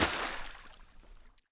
shallow-water-05.ogg